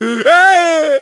bull_die_vo_04.ogg